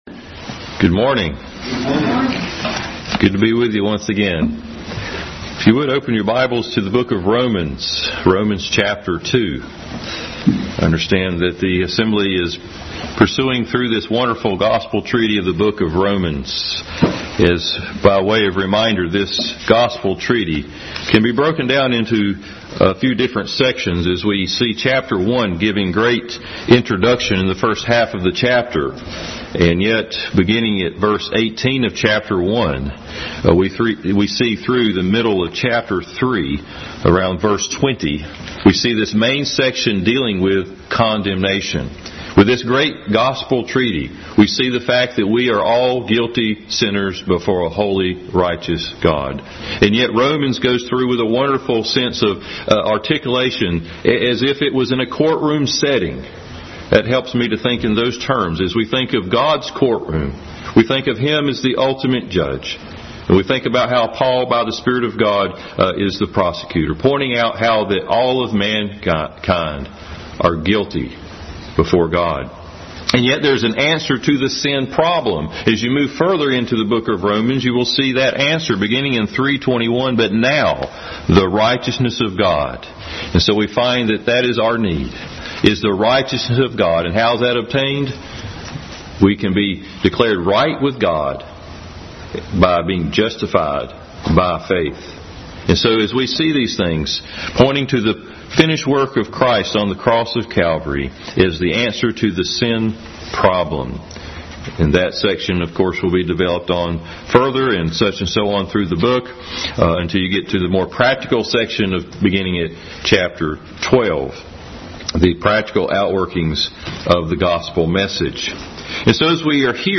Bible Text: Romans 2:1-11, Romans 3:21, 2 Samuel 12:1-7, Romans 9:1-5, Genesis 18:25, 2 Thessalonians 1:3-10 | Adult Sunday School Class continued study in the book of Romans.
Service Type: Sunday School